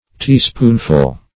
Teaspoonful \Tea"spoon`ful\ (t[=e]"sp[=oo]n`f[.u]l), n.; pl.